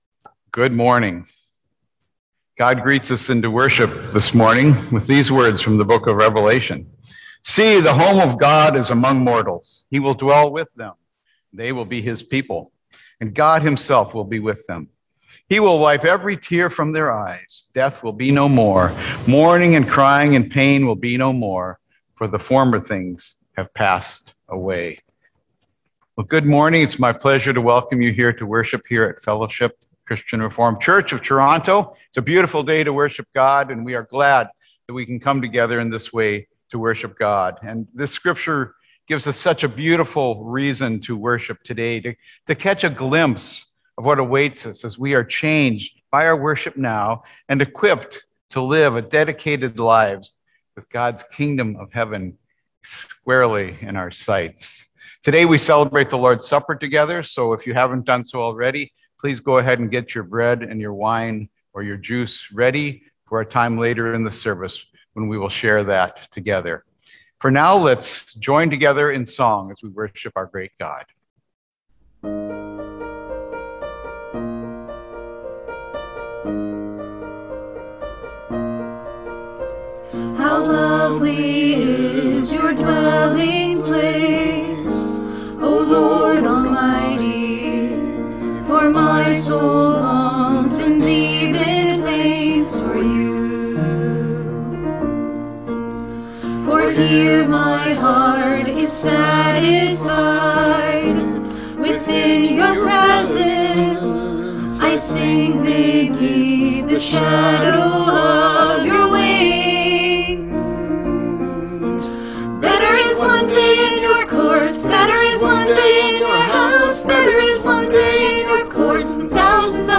(The Lord's Supper will not be a part of the recorded worship.)